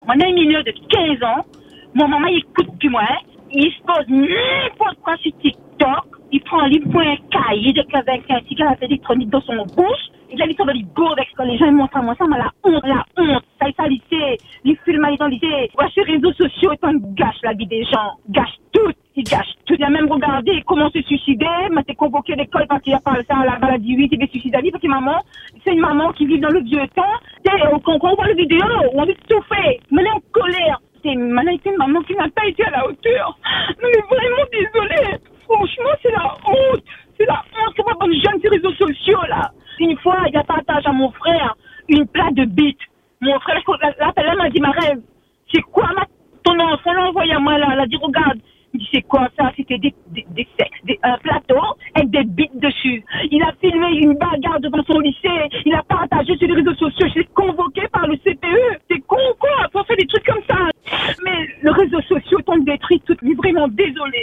C’est un appel au secours que lance cette maman.
Un témoignage brut, sans filtre, qui met en lumière le désarroi de nombreux parents face à l’omniprésence des écrans et des réseaux sociaux chez les adolescents.